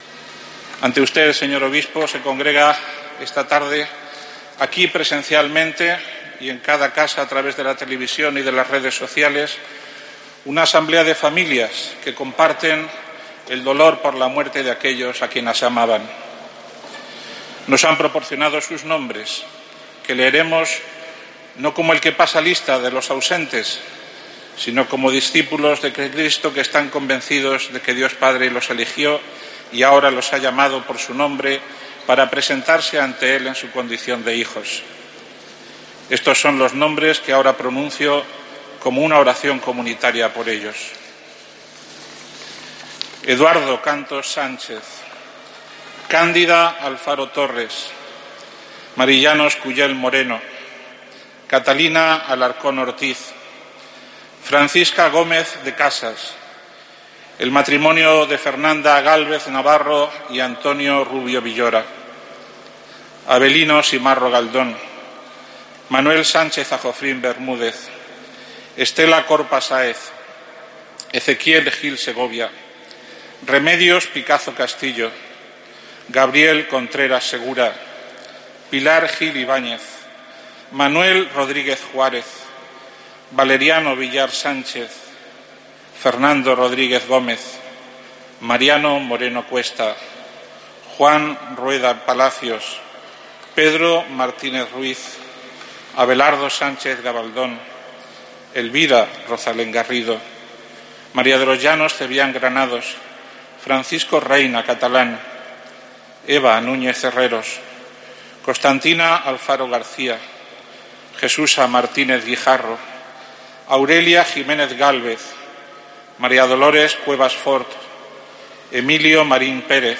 AUDIO Lista con las 143 víctimas del funeral diocesano